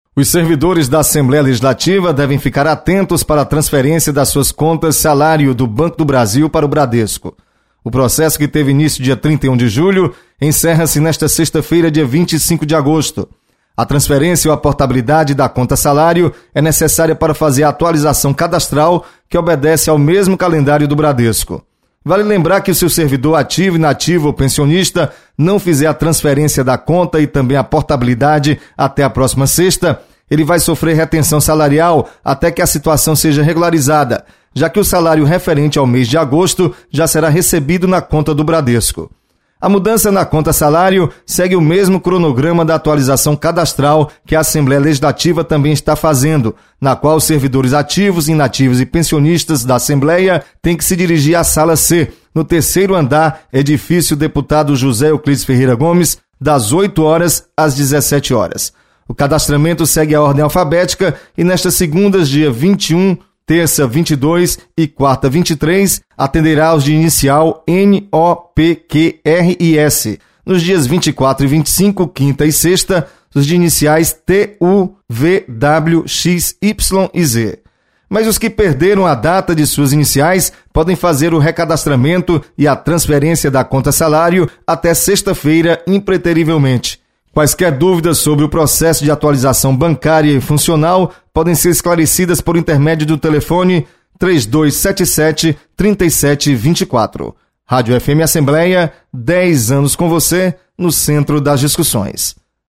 Recadastramento funcional e transferência de conta bancária para servidores da Assembleia encerra-se nesta sexta. Repórter